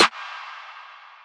TS - SNARE (1).wav